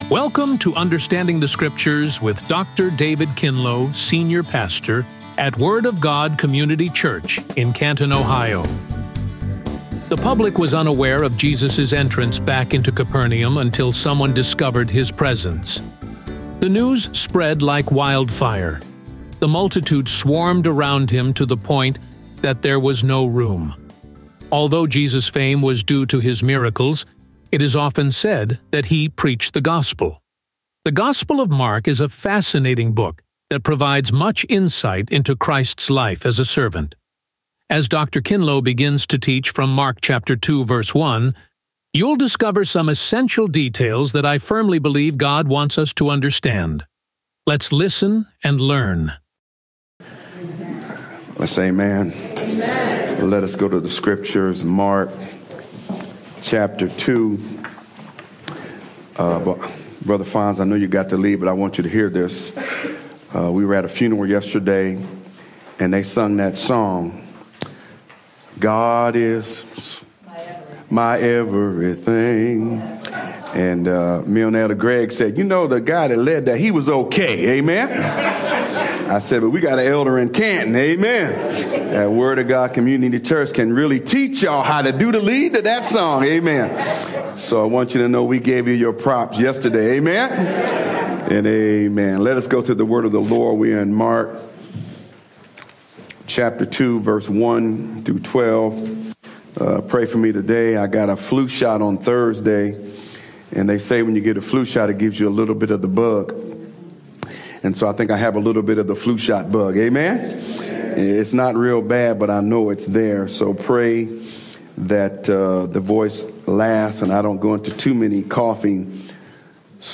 RADIO SERMON